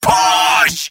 Robot-filtered lines from MvM. This is an audio clip from the game Team Fortress 2 .
Scout_mvm_cartmovingforwardoffense01.mp3